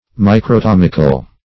Search Result for " microtomical" : The Collaborative International Dictionary of English v.0.48: Microtomic \Mi`cro*tom"ic\, Microtomical \Mi`cro*tom"ic*al\, a. Of or pert. to the microtome or microtomy; cutting thin slices.